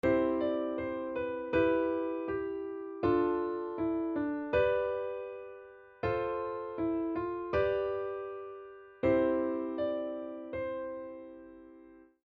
Пример взаимодействия мелодии и аккордов:
• В 1 такте ноты C, G, и B поддерживаются аккордами C и Em.
• В 4 такте нота C совпадает с аккордом C.
primer-dlya-do-mazhora.mp3